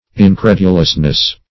Incredulousness \In*cred"u*lous*ness\, n.
incredulousness.mp3